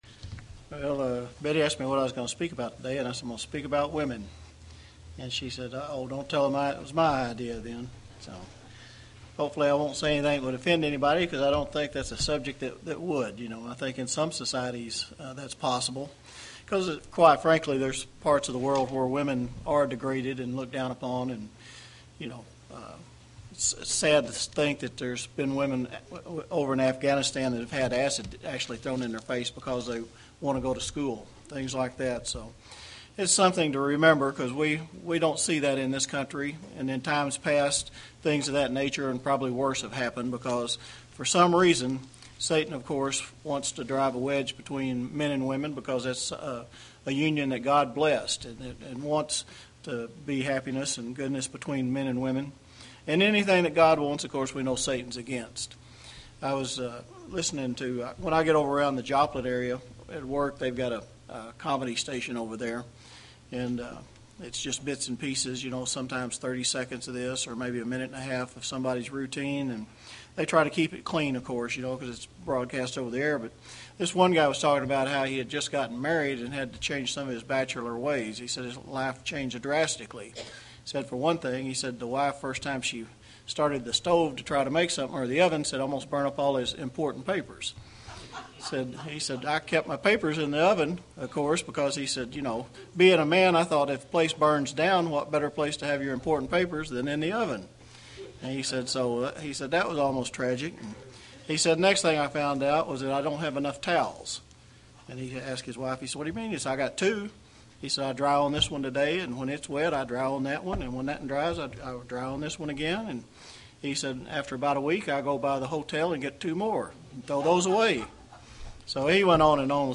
Given in Springfield, MO
UCG Sermon Studying the bible?